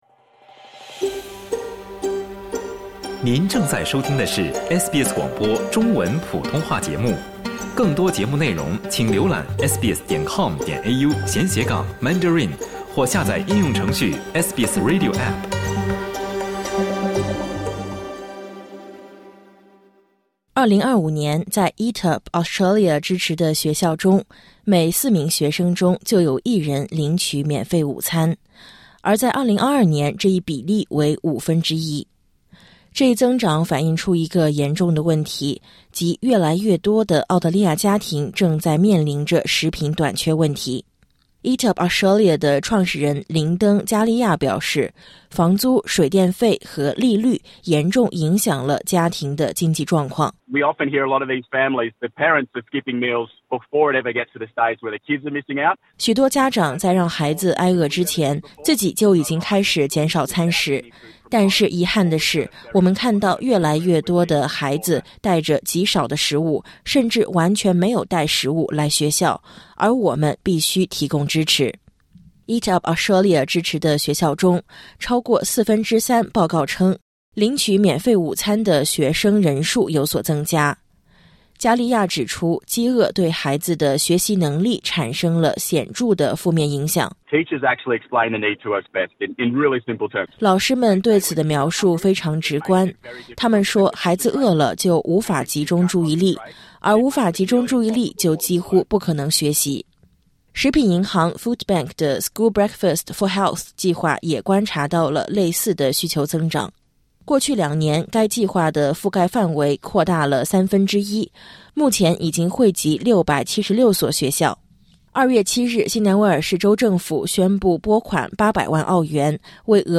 点击音频，收听完整报道。